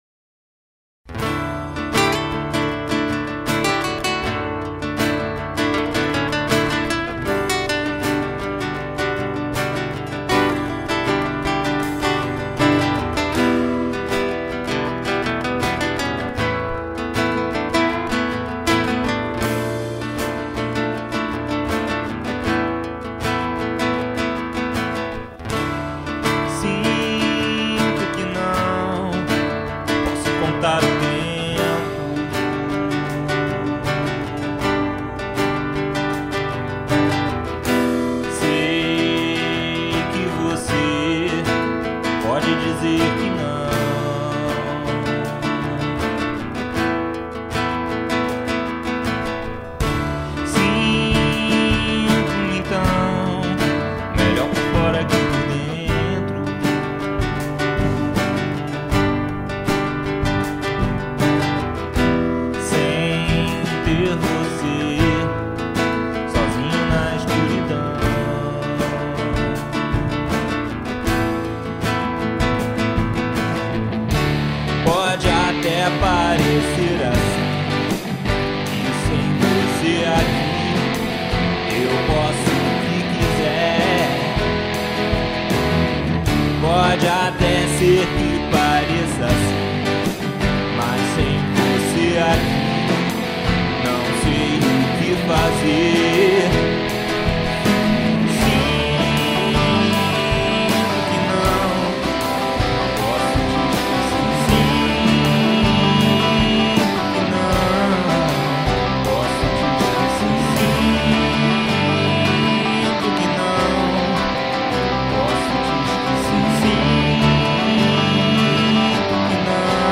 voz, guitarras, baixo e bateria eletrônica